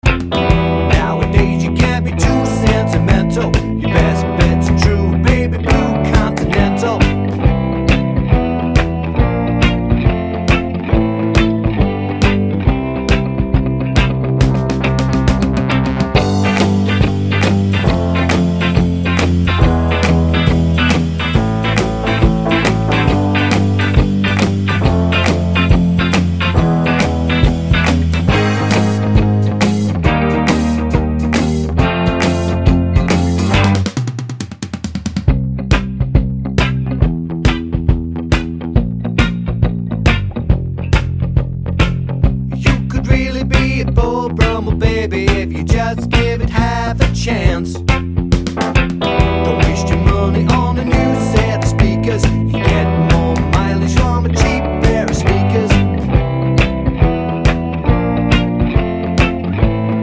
Minus Sax Pop (1980s) 2:57 Buy £1.50